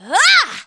p4u-yukari-whack.wav